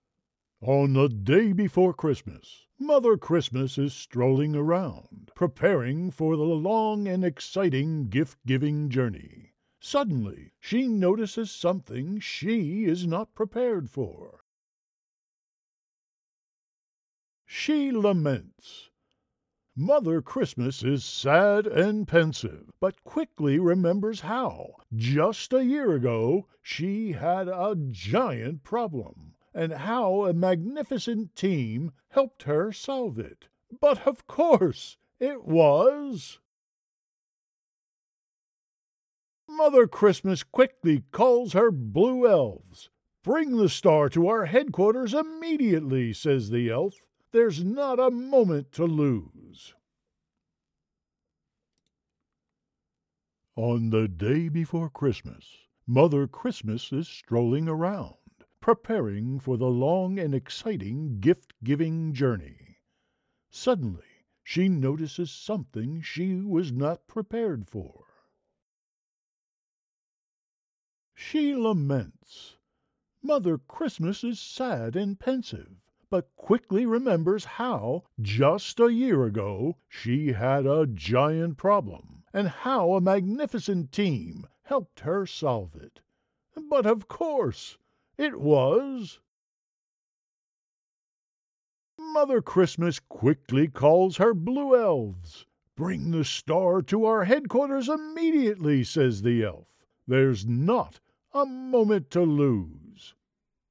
Mid Atlantic, Southern
Middle Aged
Senior
So, here I am with a MacBook Pro computer, a Neumann TLM103 microphone and the equipment to tie everything together providing broadcast quality recordings for the masses.